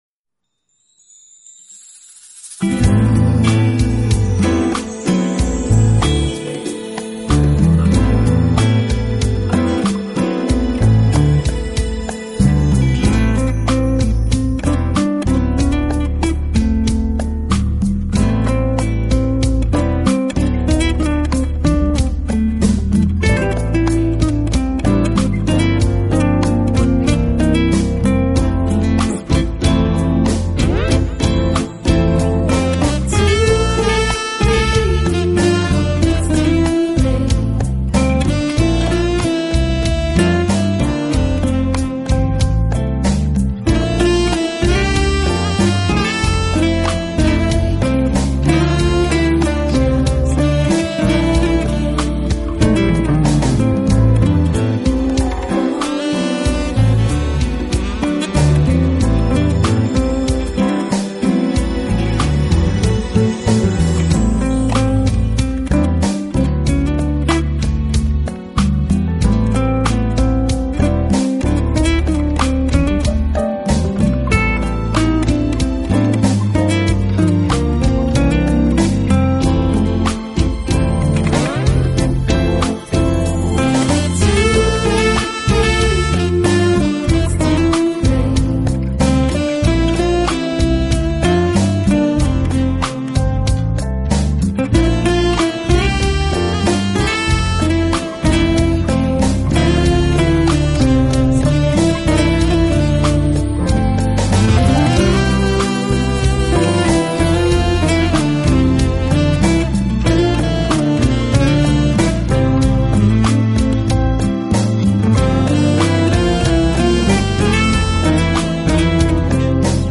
音乐类型：Jazz
Guitar, Vocals, Classical Guitar